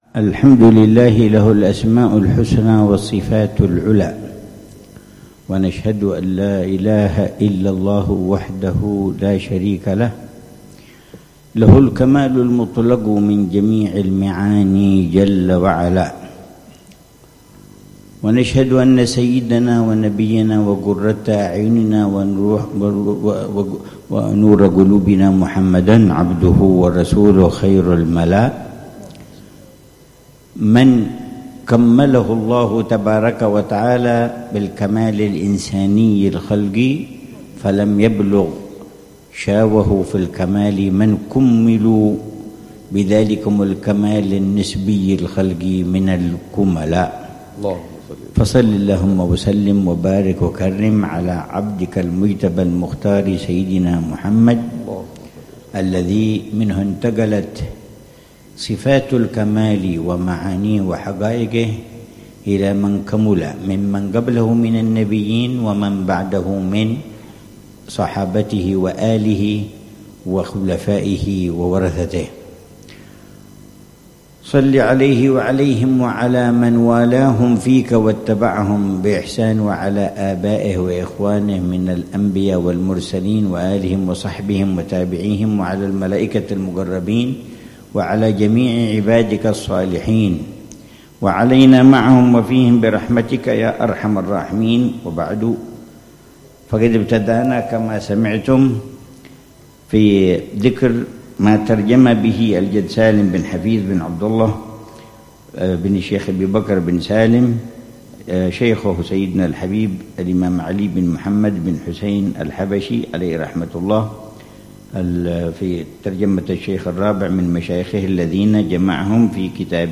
محاضرة بحثية للعلامة الحبيب عمر بن محمد بن حفيظ، في ترجمة الإمام علي الحبشي من كتاب منحة الإله للحبيب سالم بن حفيظ